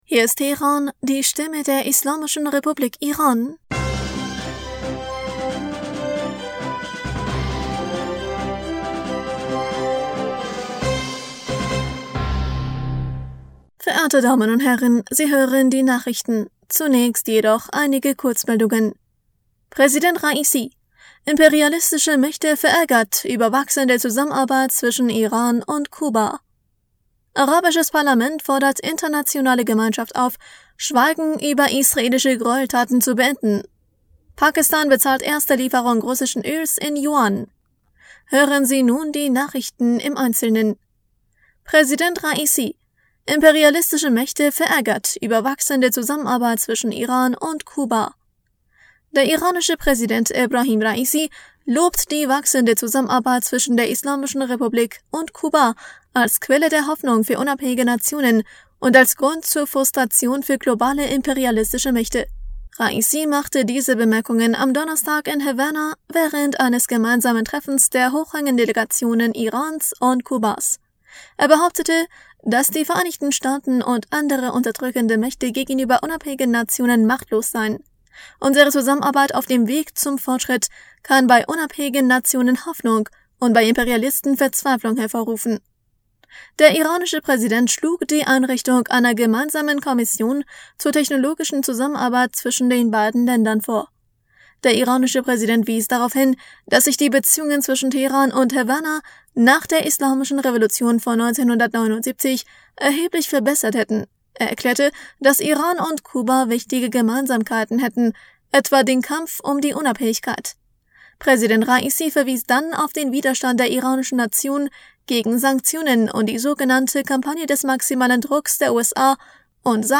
Nachrichten vom 16. Juni 2023